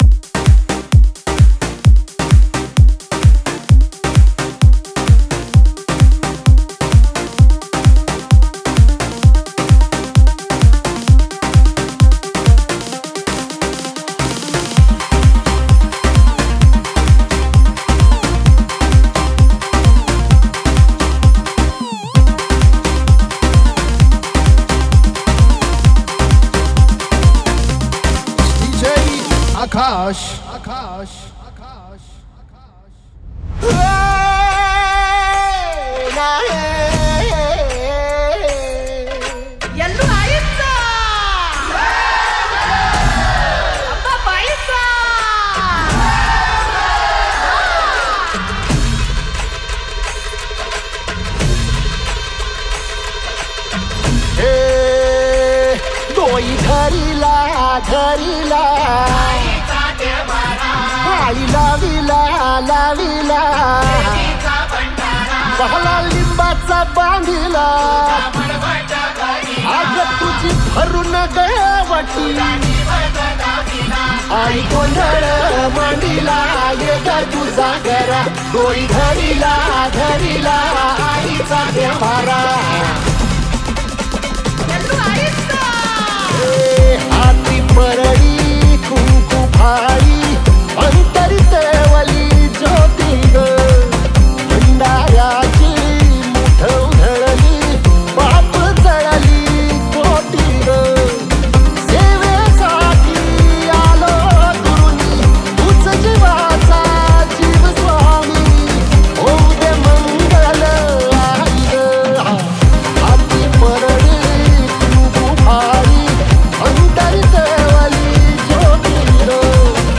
Category : Navratri Dj Remix Song